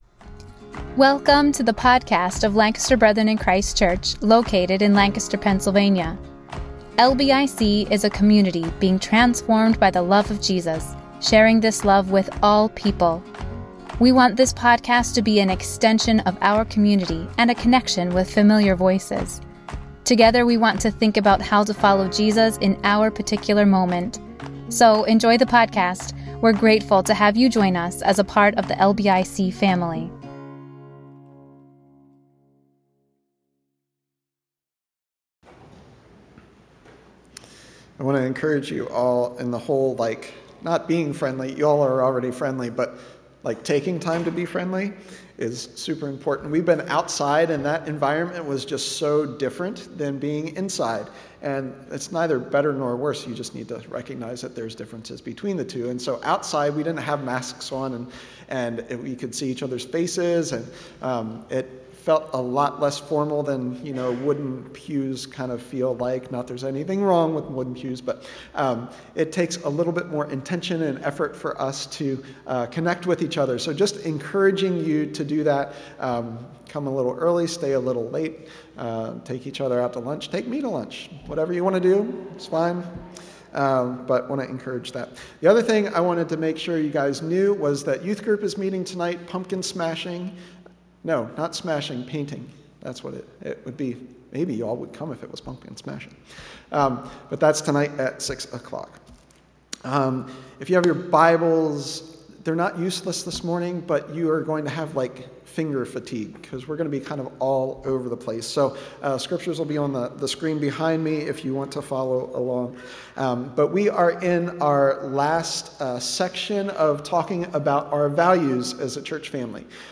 A message from the series "Values."